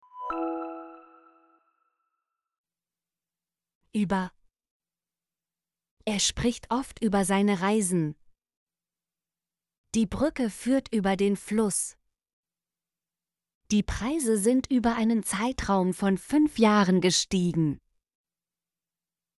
über - Example Sentences & Pronunciation, German Frequency List